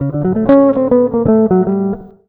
160JAZZ  4.wav